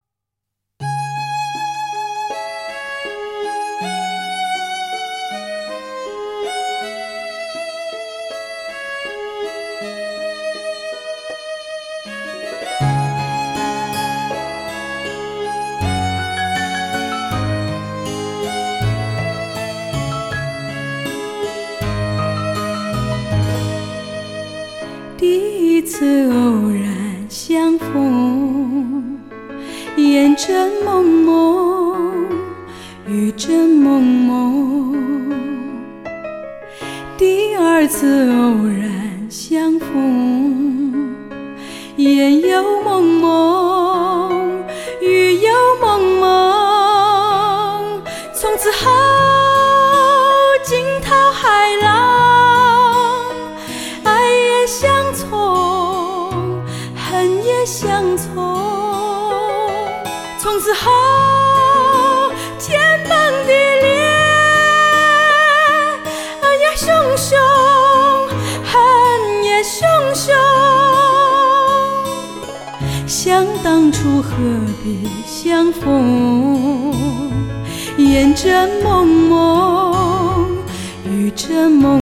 高亢的嗓音，投入的表演，